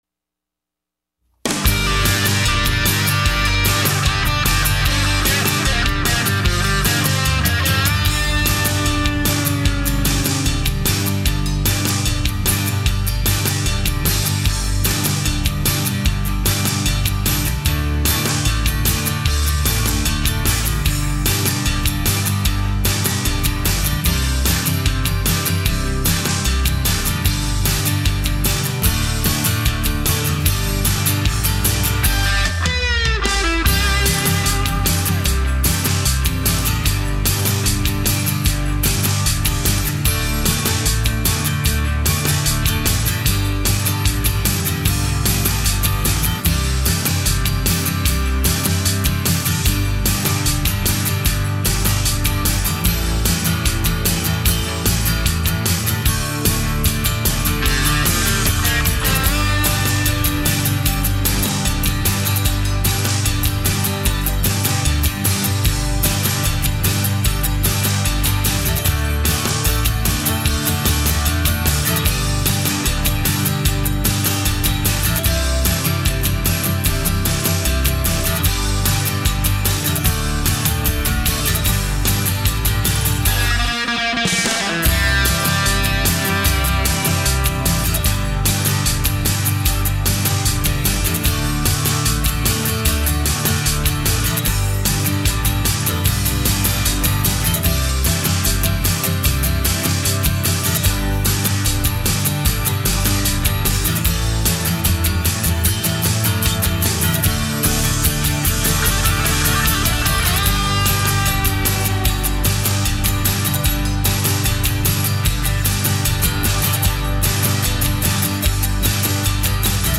Rock